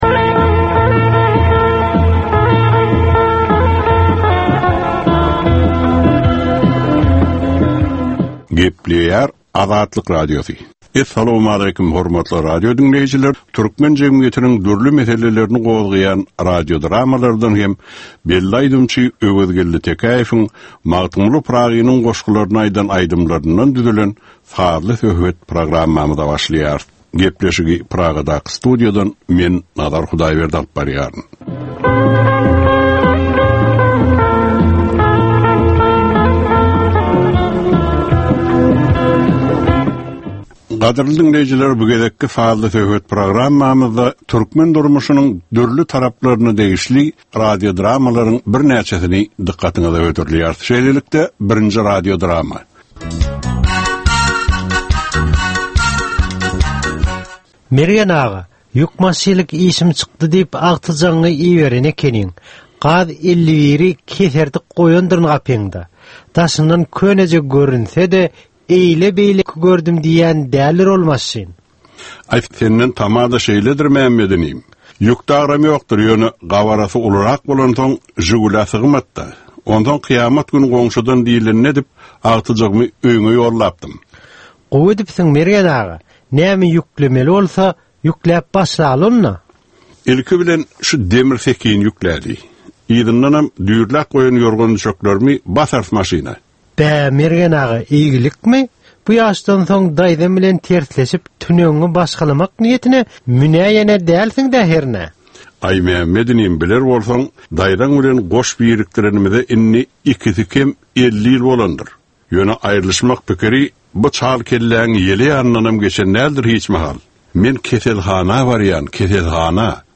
Türkmenin käbir aktual meseleleri barada 30 minutlyk sazly-informasion programma